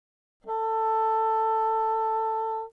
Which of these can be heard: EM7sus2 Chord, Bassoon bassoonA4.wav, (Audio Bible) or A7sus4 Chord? Bassoon bassoonA4.wav